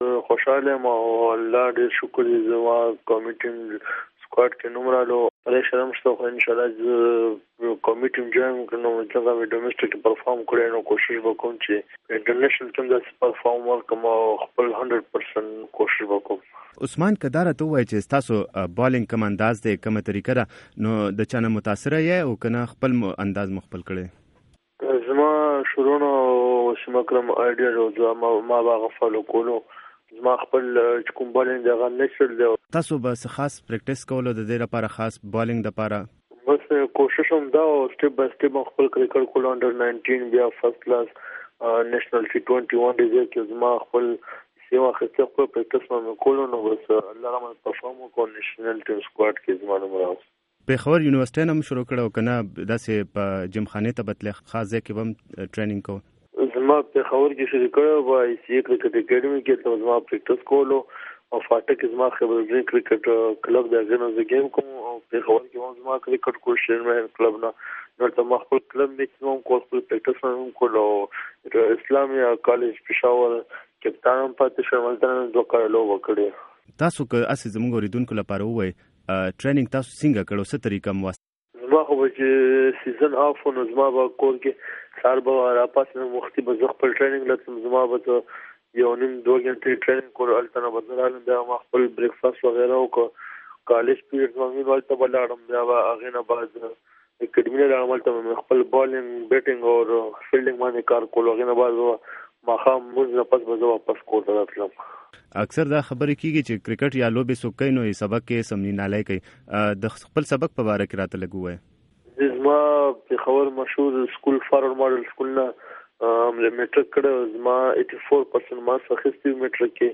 د وائس اف امریکا ډیوه ریډیو مرکه